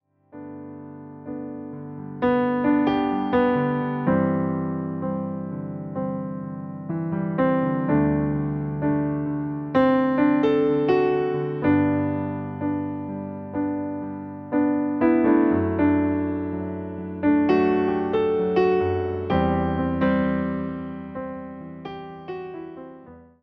Wersja demonstracyjna:
127 BPM
G – dur